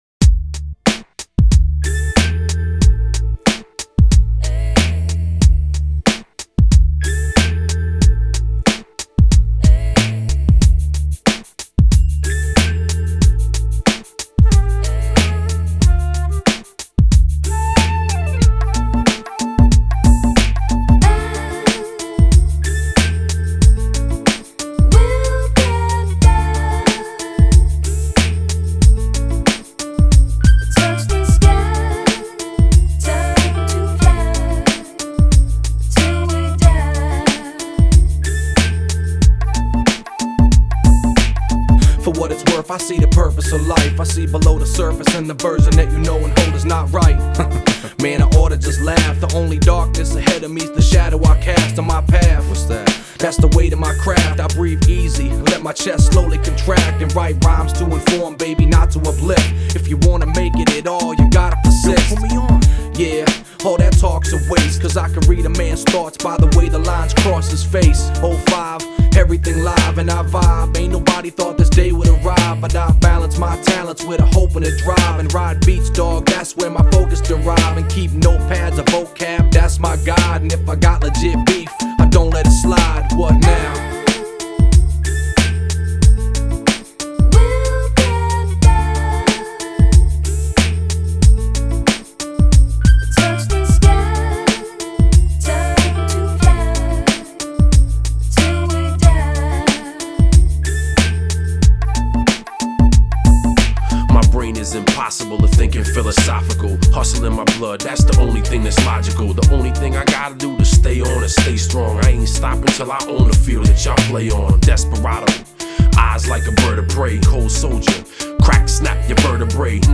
это энергичная рэп-композиция